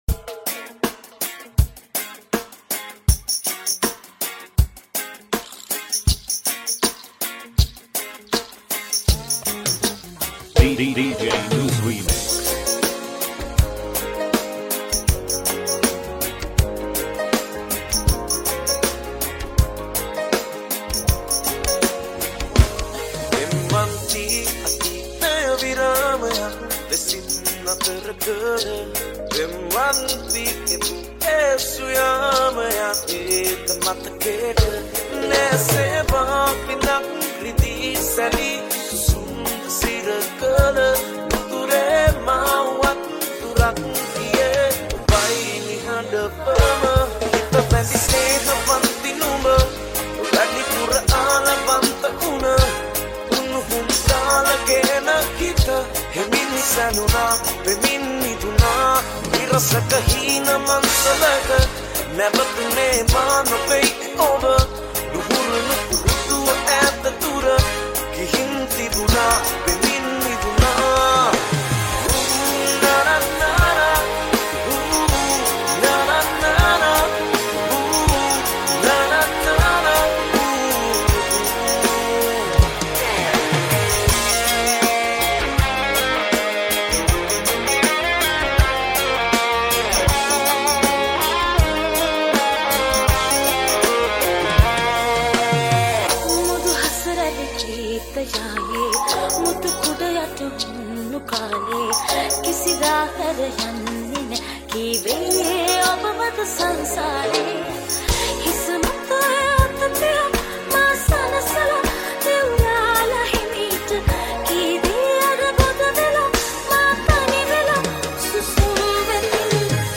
Sinhala Reggae Medley Remix